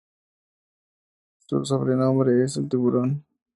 so‧bre‧nom‧bre
/sobɾeˈnombɾe/